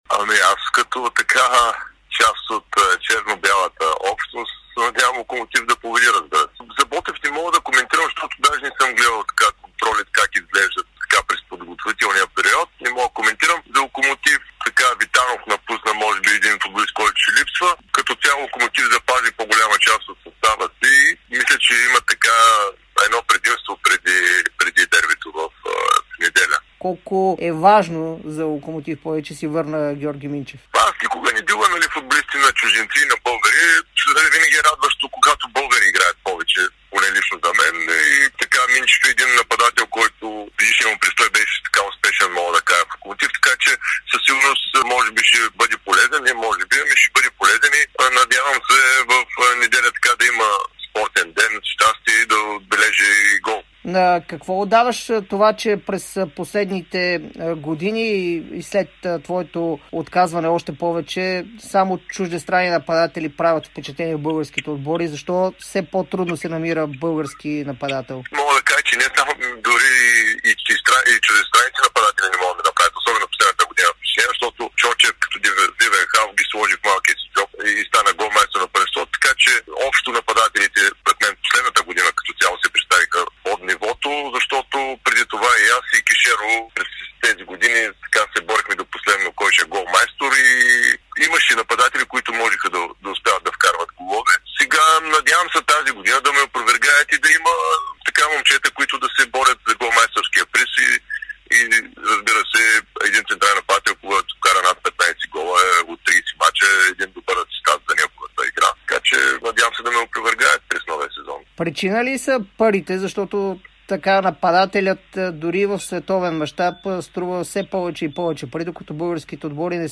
Легендарният български голмайстор Мартин Камбуров даде интервю за Дарик радио и dsport преди пловдивското дерби между бившите му отбори Локомотив и Ботев. Той коментира и битката на върха в родния елит, както и слабото представяне на нападателите в България през миналия сезон.